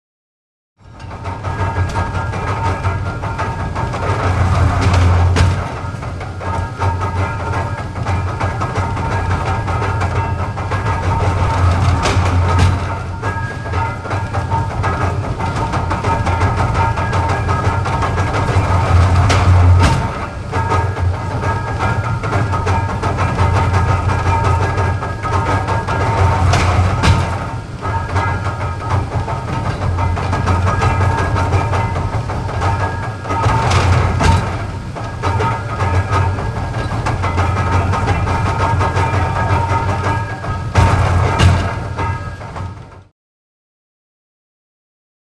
Bailing Machine | Sneak On The Lot
Close Heavy Slow Jangling Noise.